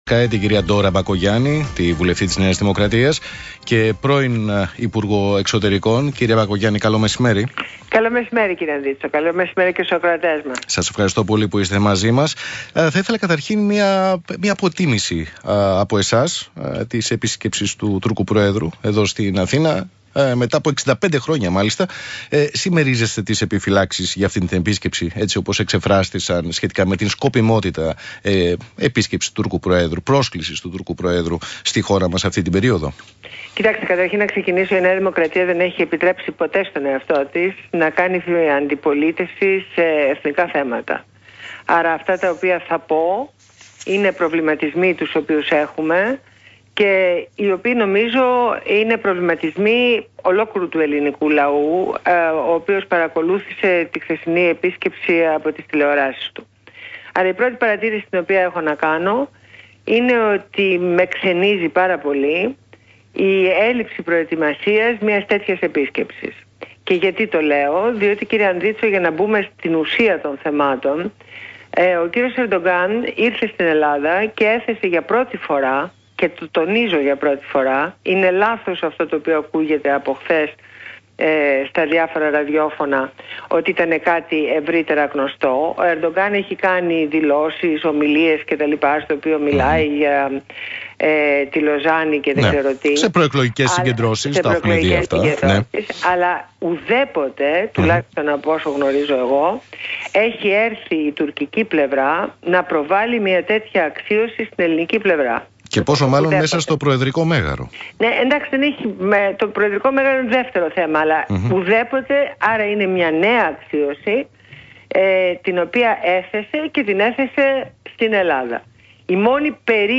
Συνέντευξη στο ραδιόφωνο του ΣΚΑΙ